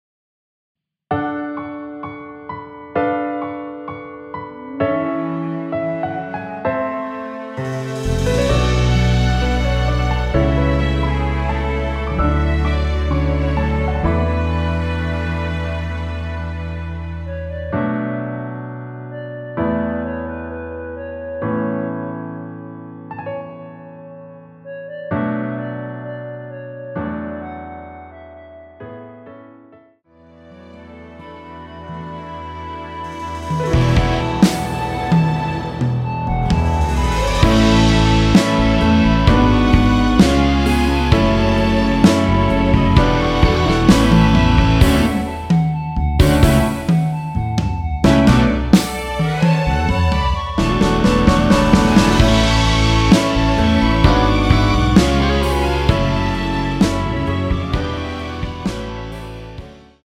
원키에서(-1)내린 멜로디 포함된 MR입니다.(미리듣기 확인)
◈ 곡명 옆 (-1)은 반음 내림, (+1)은 반음 올림 입니다.
앞부분30초, 뒷부분30초씩 편집해서 올려 드리고 있습니다.